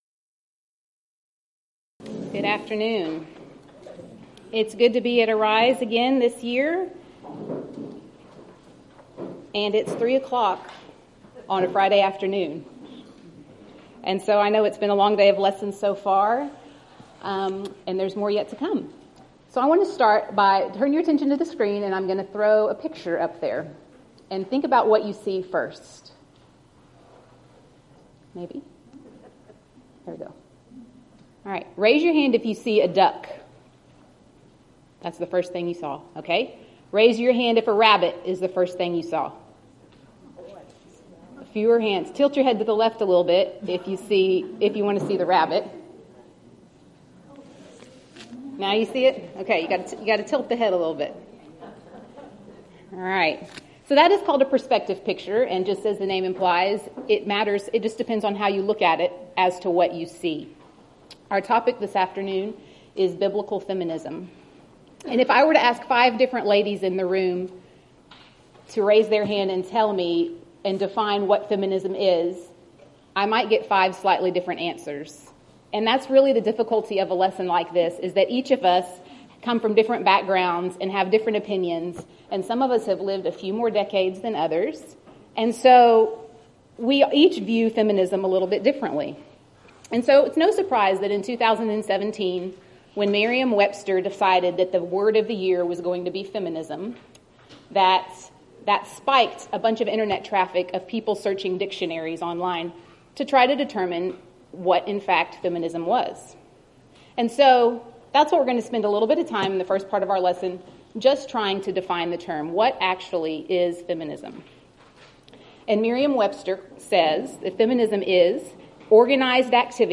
Event: 3rd Annual Southwest Spritual Growth Workshop
Ladies Sessions